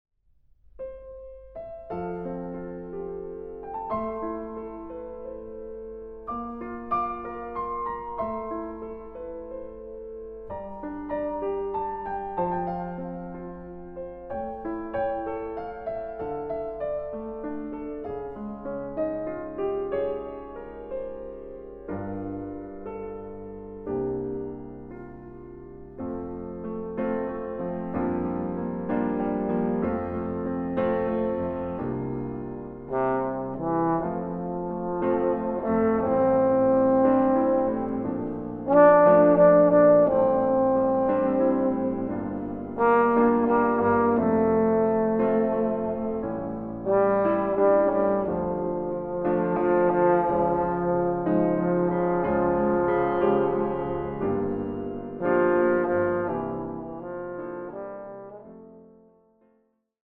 Recording: Mendelssohn-Saal, Gewandhaus Leipzig, 2025
Version for Euphonium and Piano